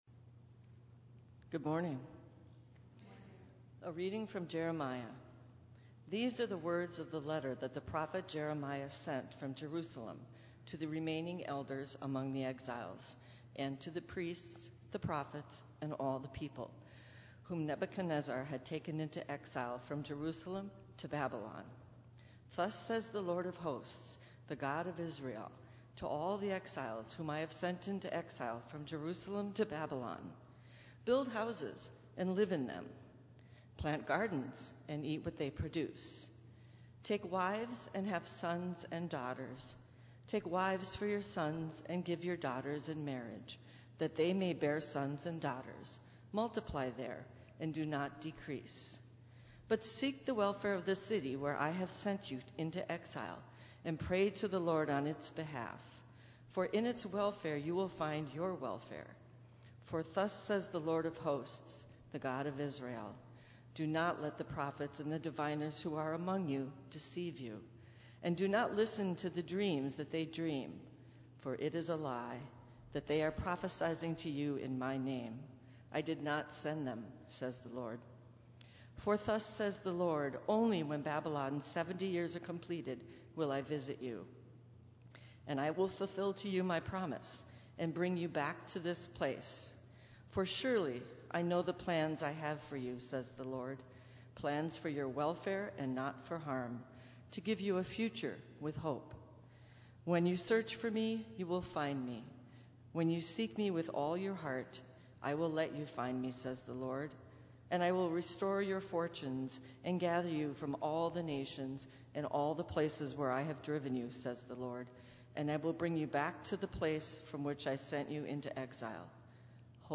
Minnetonka Livestream · Sunday, July 3, 2022 9:30 am